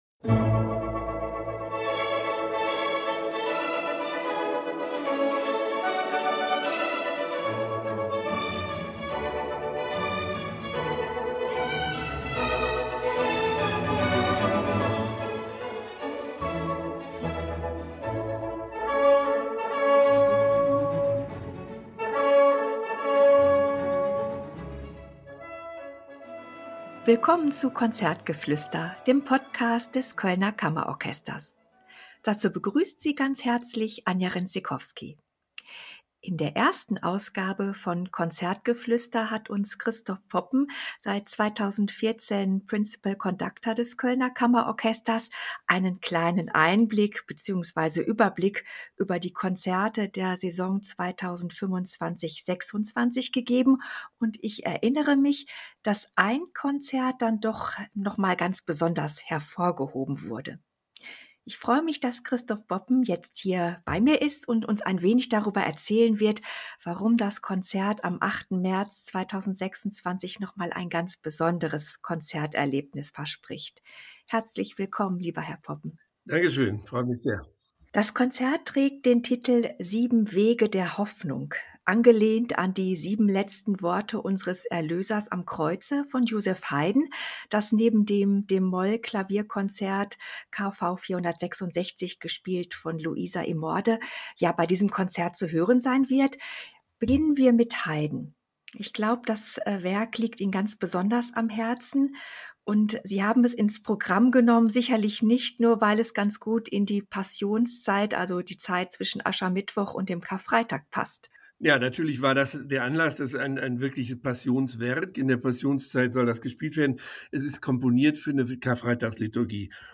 Zudem erfahren wir, was es mit der spannenden Enstehungsgeschichte auf sich hat und wie Thomas Bernhards Gedichte aus "In hora mortis" - gelesen vom wunderbaren Schauspieler Joachim Król - dazu passen.